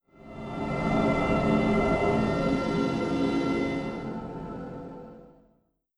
Playstation 6 Startup.wav